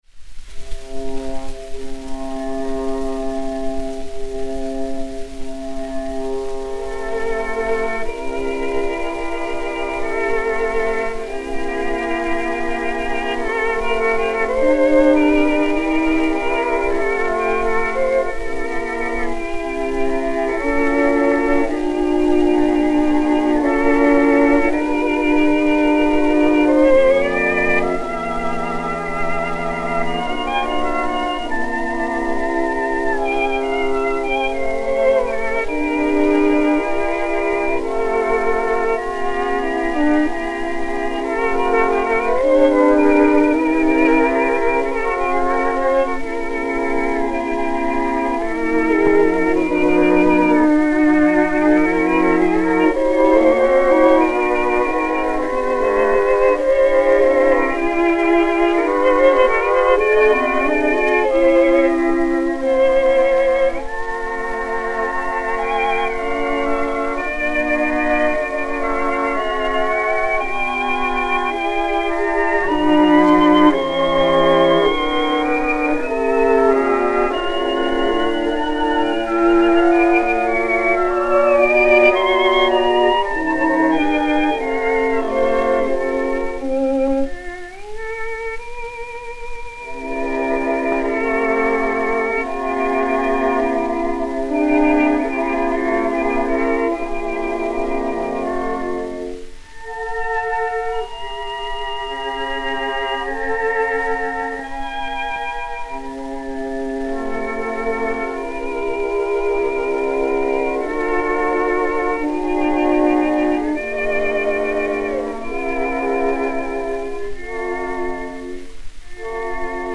Fritz Kreisler (violon) et Quatuor à cordes
Gramophone DA 264, mat. 4-7964, enr. le 29 mai 1916